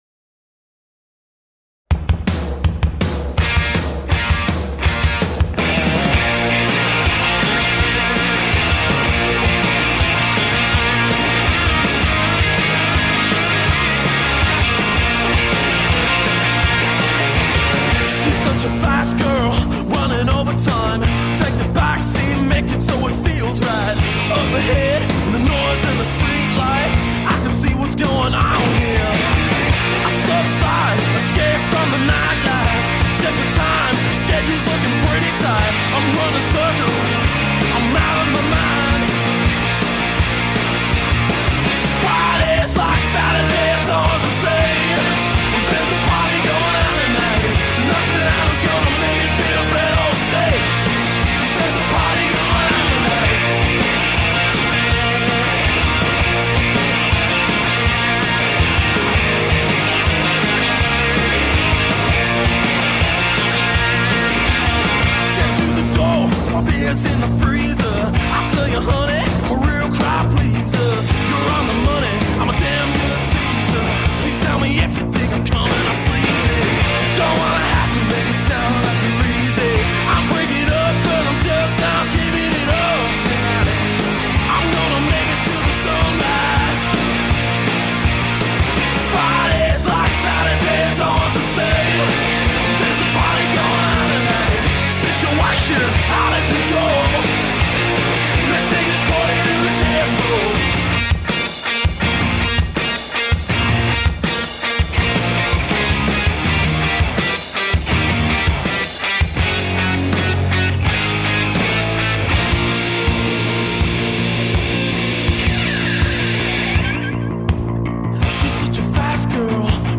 Rock and roll from the heart.
Tagged as: Hard Rock, Punk